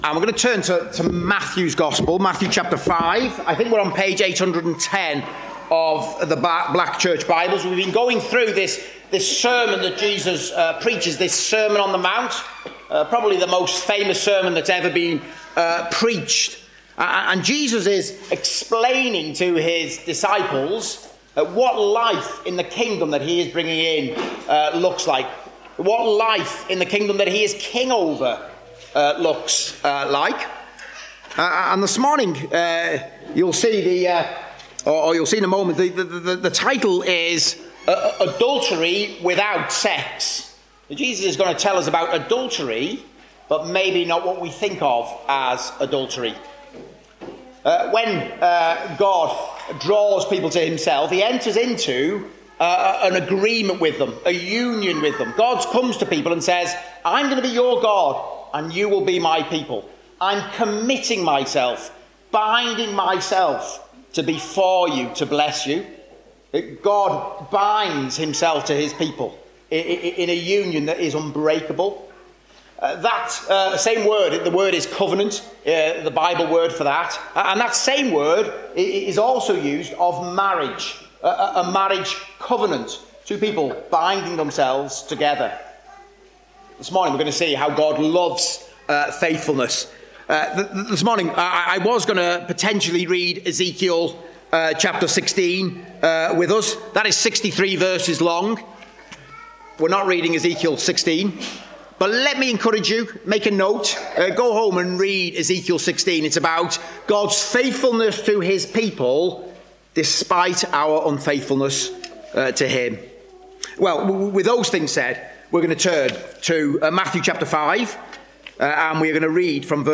Service Type: Preaching